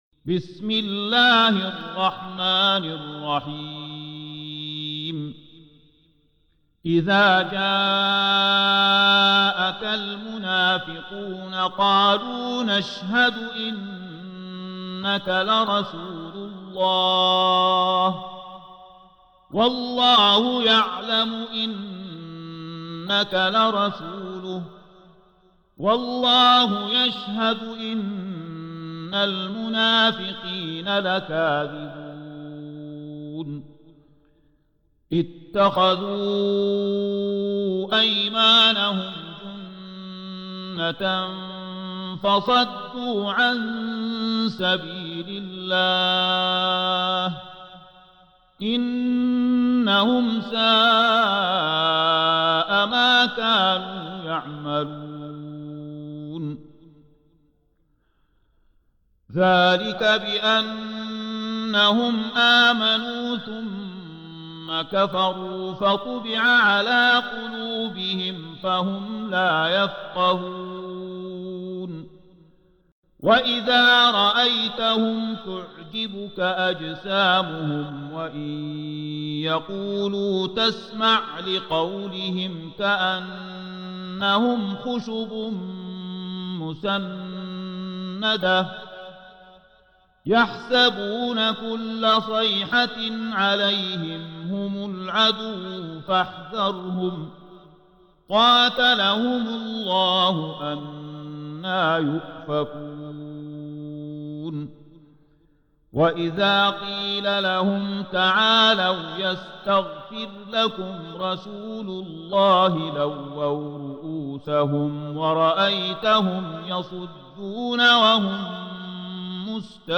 Surah Sequence تتابع السورة Download Surah حمّل السورة Reciting Murattalah Audio for 63.